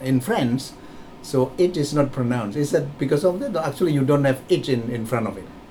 S1 = Taiwanese female S2 = Indonesian male Context: They are discussing the alternative pronunciation of names, particularly whether a name such as Henry should be pronounced with an 'h' at the start.
For the first token, there is minimal fricative noise after the release of the [t], lasting about 20 msec; so it sounds more like the minimal aspiration on a final [t] than the fricative part of [tʃ] . The affricate is clearer in the second token, but the vowel has a close quality, so it is heard by S1 as [ɪ] rather than [eɪ] .